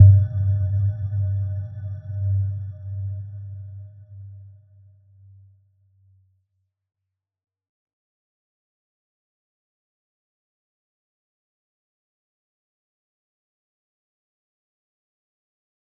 Little-Pluck-G2-f.wav